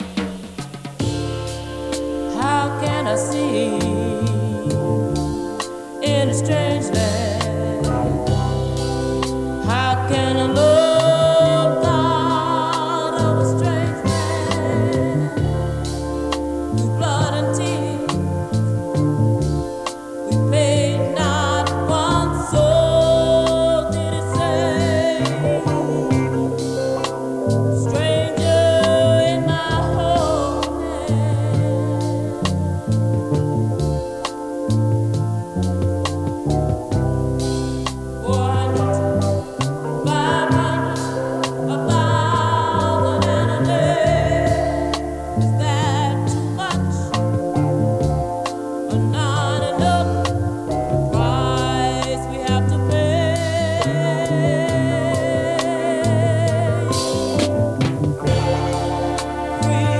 psychedelic funk ballad